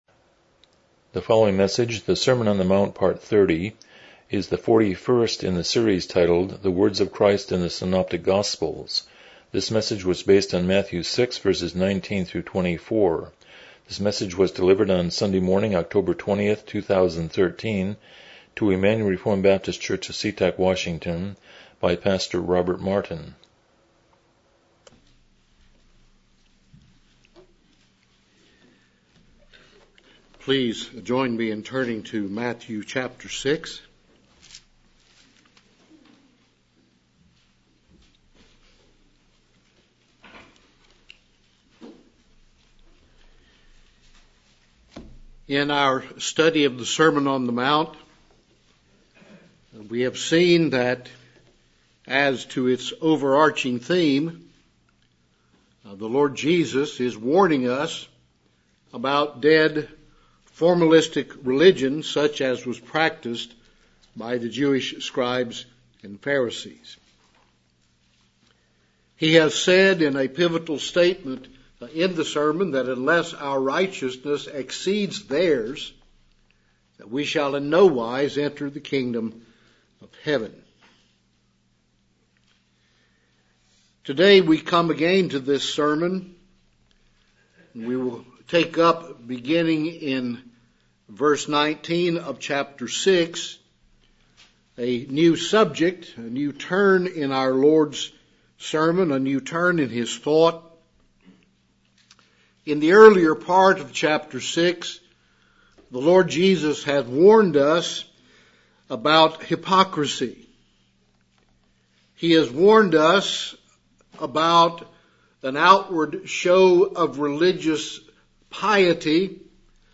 The Words of Christ in the Synoptic Gospels Passage: Matthew 6:19-24 Service Type: Morning Worship « 7 Who is God? 3 8 Who is Man?